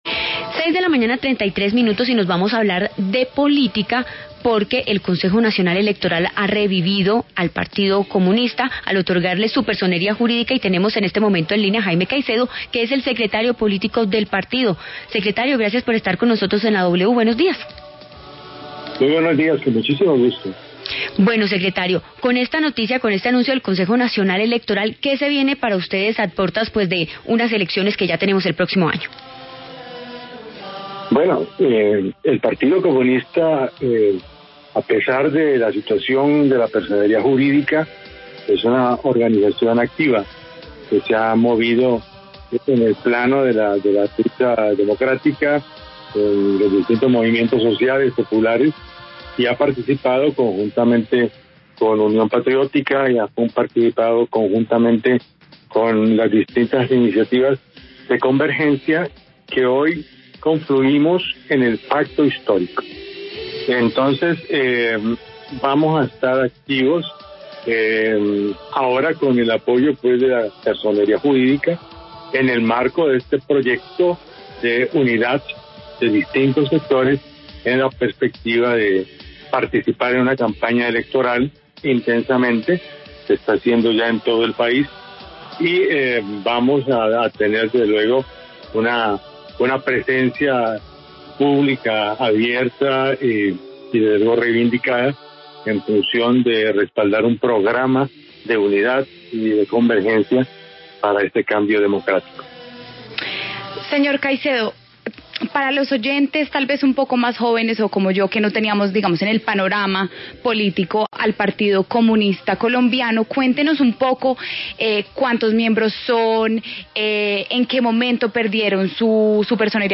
El secretario político del partido, Jaime Caycedo, se refirió en La W a las próximas elecciones presidenciales.
Entrevista Jaime Caycedo